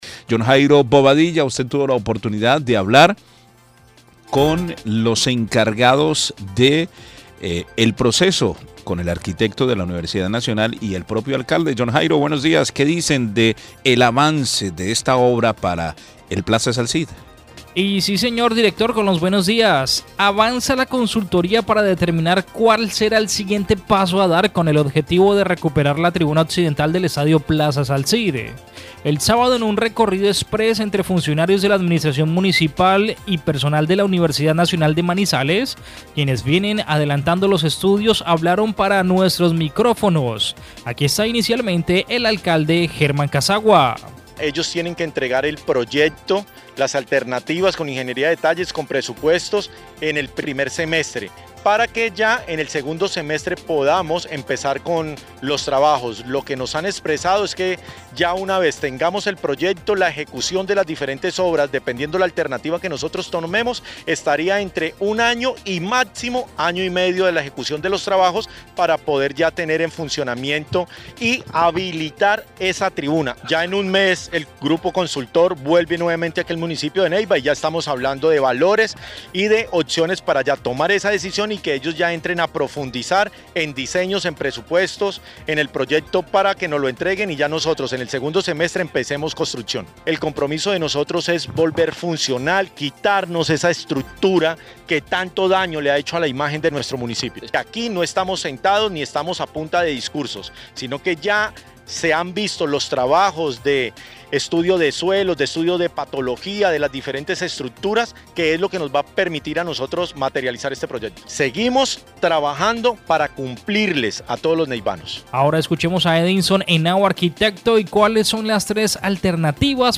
Avanza la consultoría para determinar cuál será el siguiente paso a dar con el objetivo de recuperar la tribuna occidental del estadio Plazas Alcid, este sábado en un recorrido express entre funcionarios de la administración municipal y personal de la Universidad Nacional de Manizales quienes vienen adelantando los estudios hablaron para nuestros micrófonos, aquí está el Alcalde Germán Casagua inicialmente.
El Concejal Cristian Bautista viene haciendo veeduría a la decisión que se logre tomar, esto nos expresó.